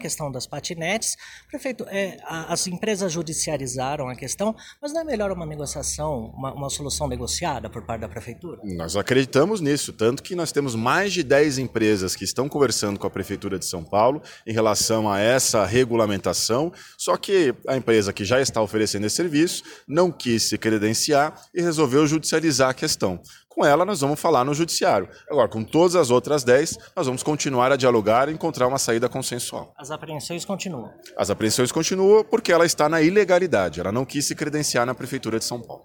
Na manhã desta sexta-feira, o prefeito de São Paulo, Bruno Covas, disse ao Diário do Transporte, que a gestão conversa com dez empresas interessadas em oferecer o aluguel de patinetes na cidade, mas que com a Grow, agora o entendimento é somente judicial.